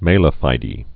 (mālə fīdē, mälä fēdĕ)